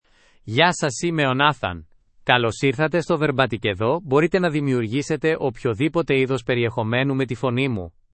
Nathan — Male Greek (Greece) AI Voice | TTS, Voice Cloning & Video | Verbatik AI
Nathan is a male AI voice for Greek (Greece).
Voice sample
Nathan delivers clear pronunciation with authentic Greece Greek intonation, making your content sound professionally produced.